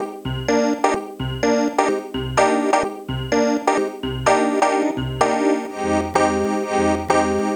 synthe_refrain_bis [..> 2025-11-16 12:42  4.2M